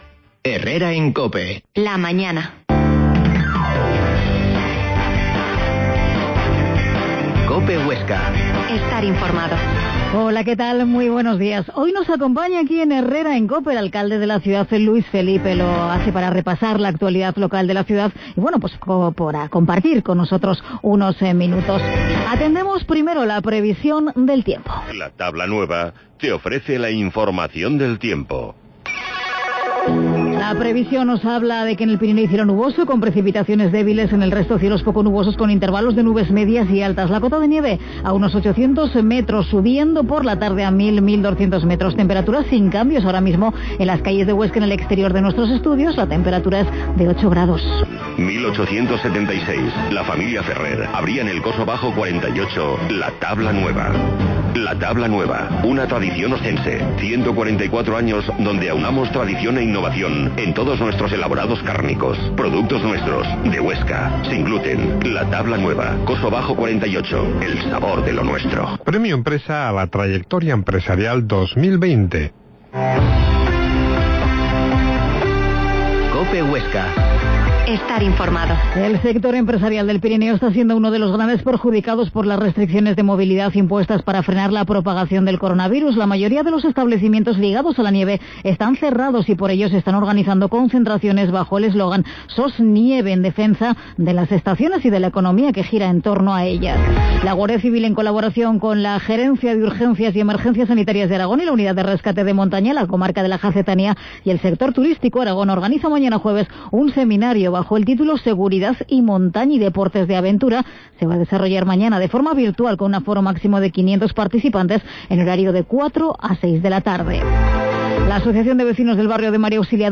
Herrera en COPE Huesca 12.50h Entrevista al alcalde de Huesca, Luis Felipe